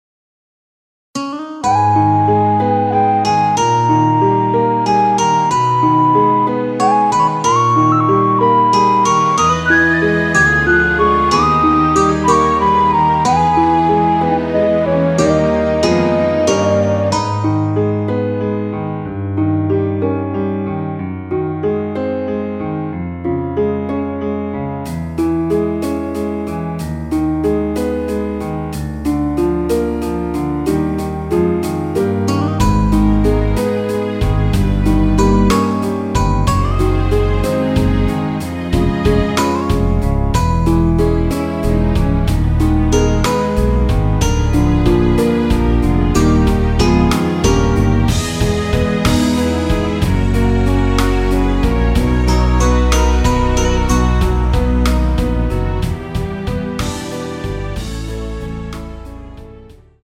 Abm
◈ 곡명 옆 (-1)은 반음 내림, (+1)은 반음 올림 입니다.
앞부분30초, 뒷부분30초씩 편집해서 올려 드리고 있습니다.
중간에 음이 끈어지고 다시 나오는 이유는